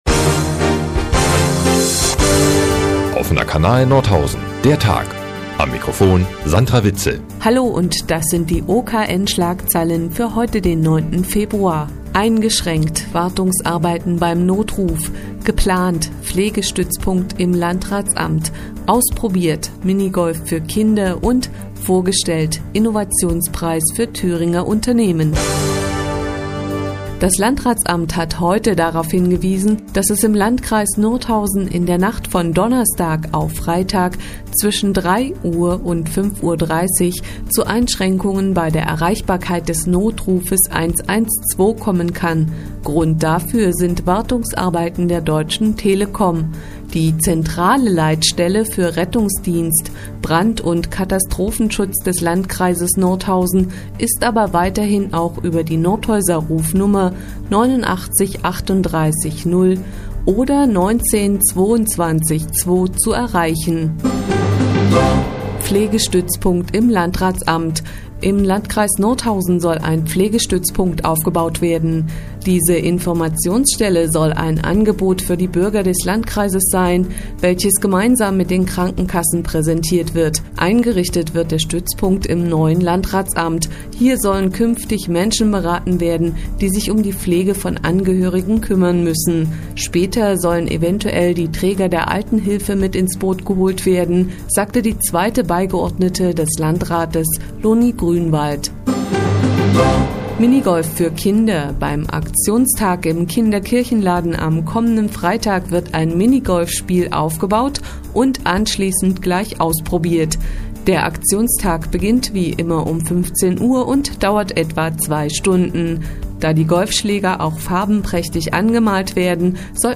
Die tägliche Nachrichtensendung des OKN ist nun hier zu hören.